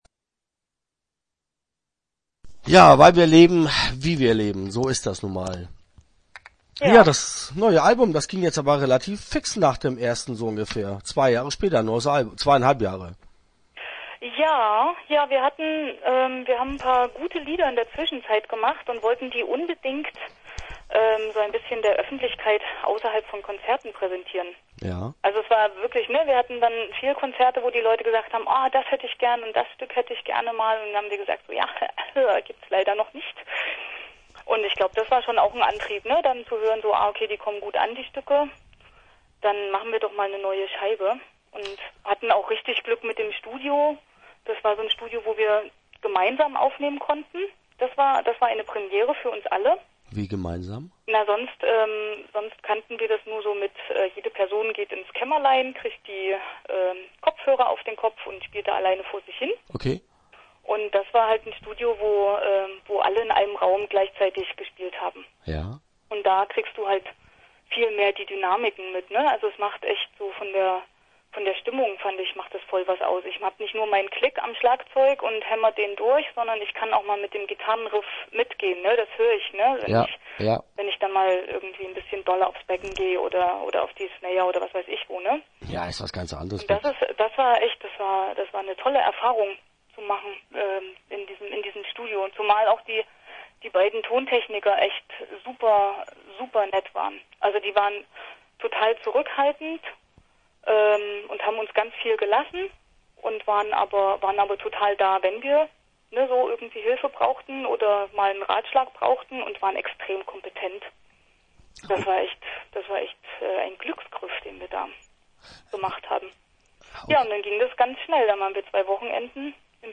Interview Teil 1 (10:26)